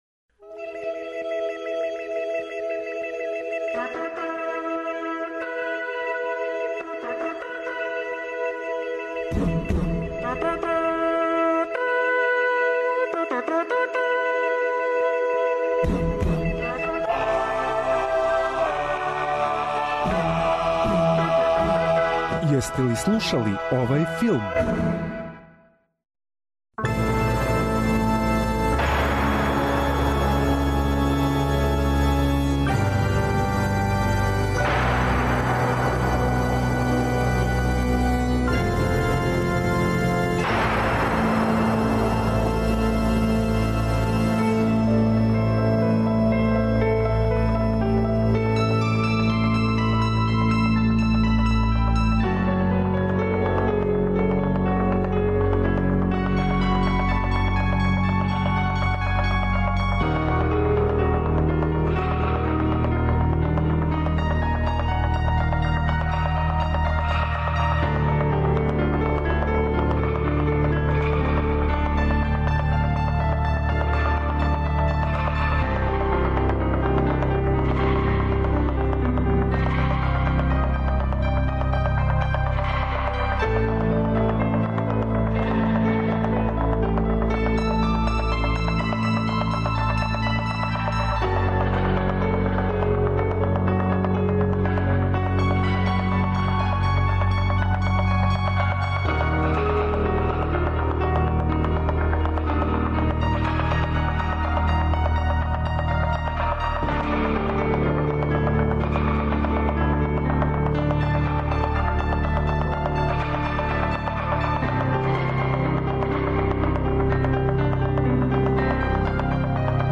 Филмска музика и филмске вести.